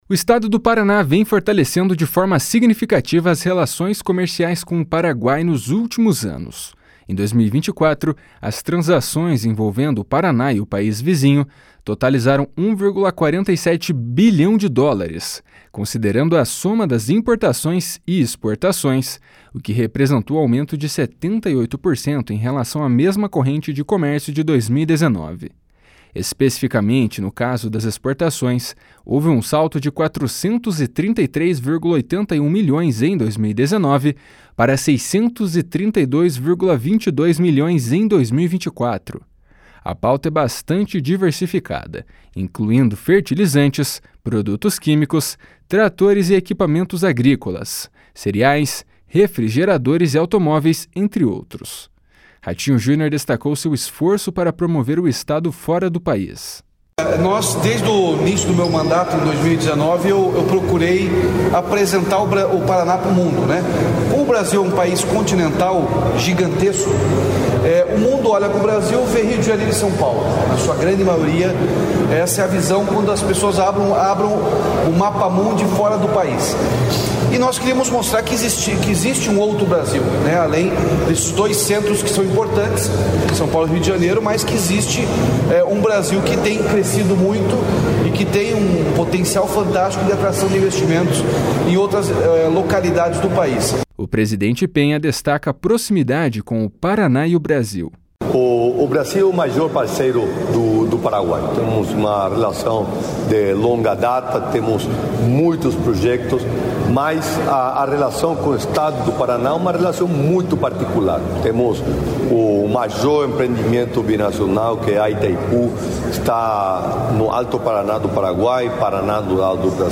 // SONORA RATINHO JUNIOR //
O presidente Peña destaca a proximidade com o Paraná e o Brasil. // SONORA SANTIAGO PEÑA //